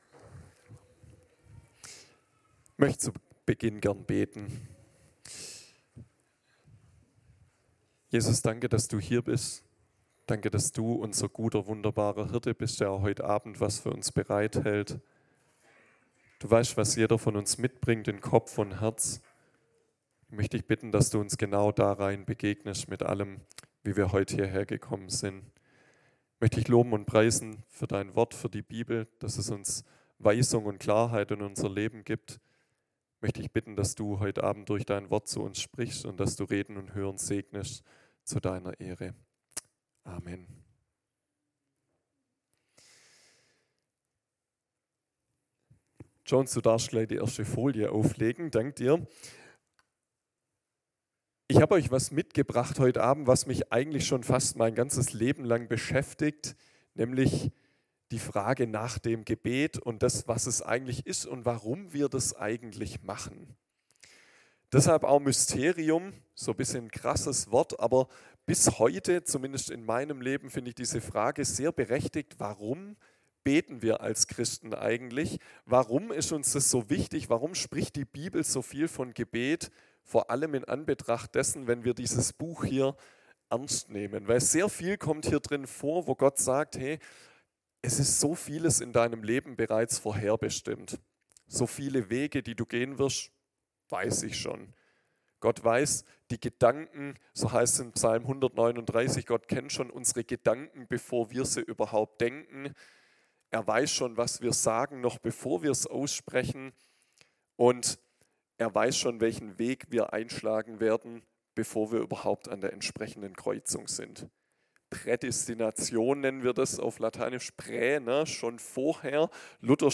Go In Gottesdienst am 21.01.2024